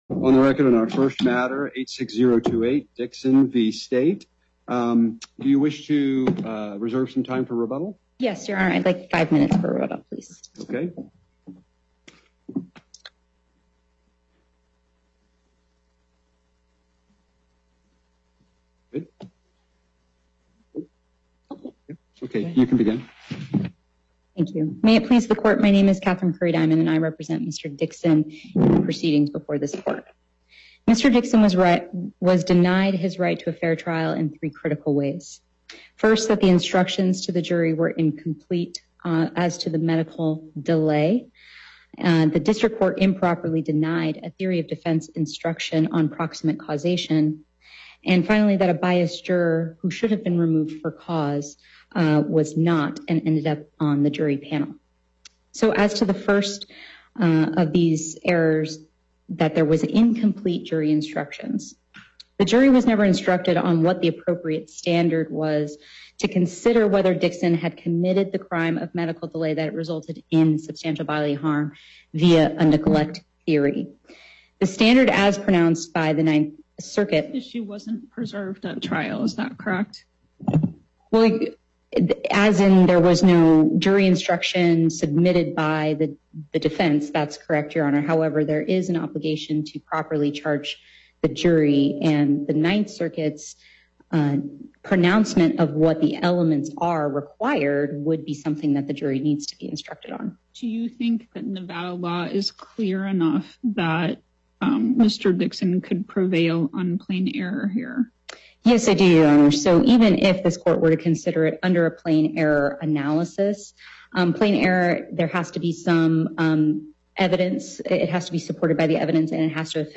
Before Panel A24, Justice Herndon presiding Appearances